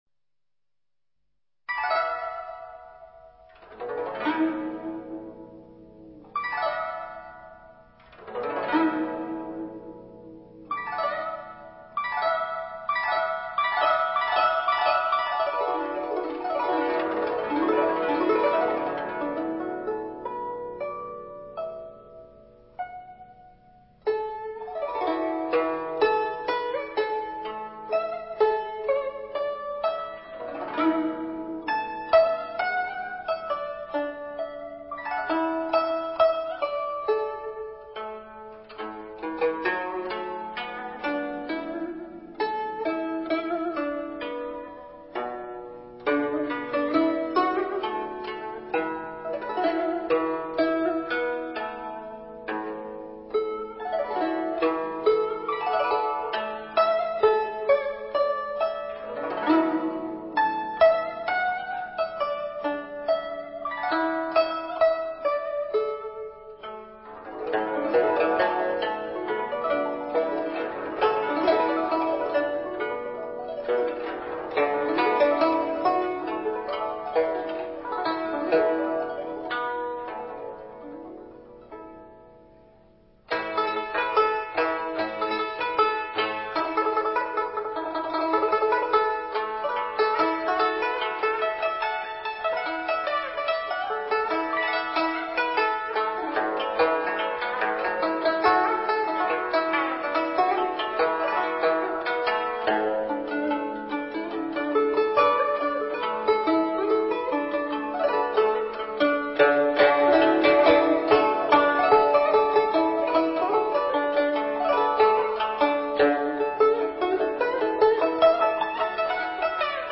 洞庭新歌-古筝--未知 冥想 洞庭新歌-古筝--未知 点我： 标签: 佛音 冥想 佛教音乐 返回列表 上一篇： 春苗-古筝--未知 下一篇： 纺织忙-古筝--未知 相关文章 佛法与日常生活的关系A--梦参法师 佛法与日常生活的关系A--梦参法师...